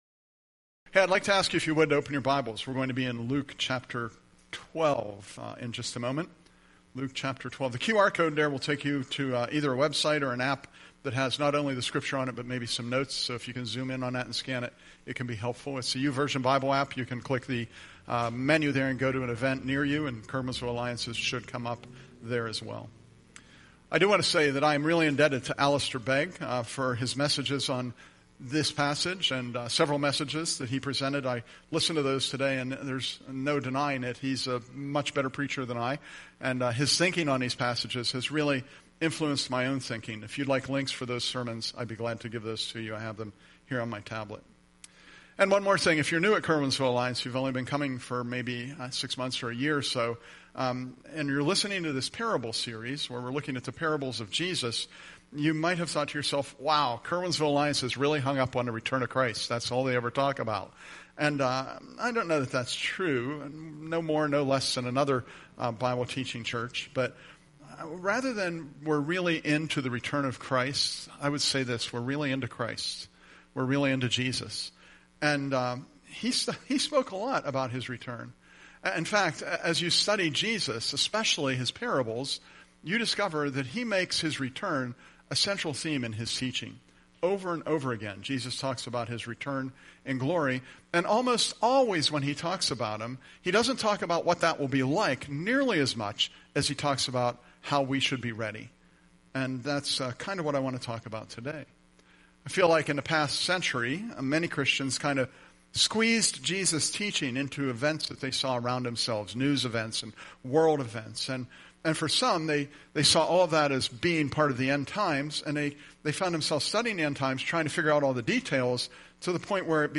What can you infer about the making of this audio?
Presented at Curwensville Alliance on 8/31/25